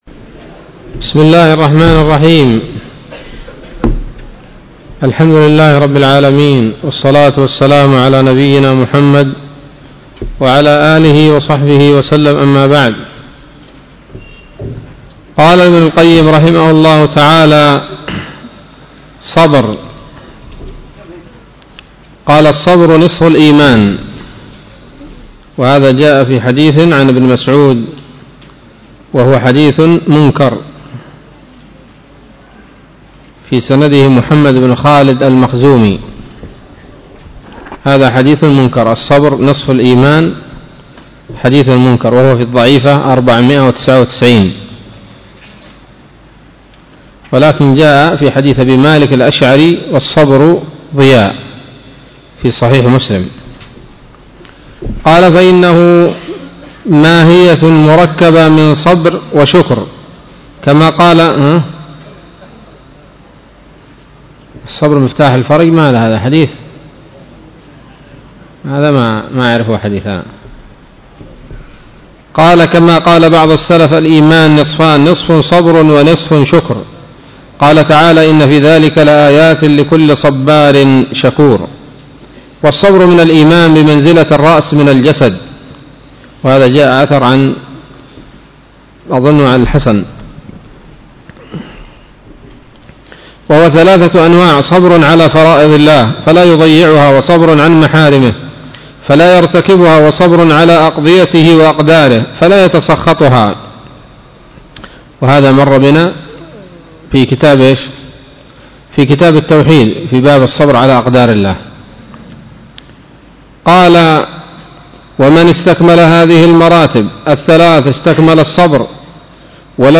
الدرس التاسع والثمانون من كتاب الطب النبوي لابن القيم